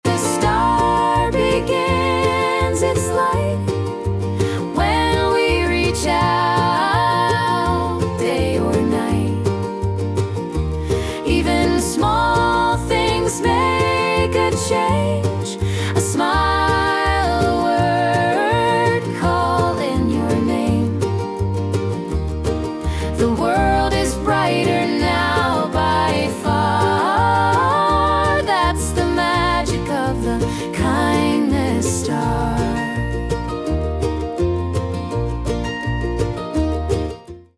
• 💫 Beautiful vocals and a timeless message for all ages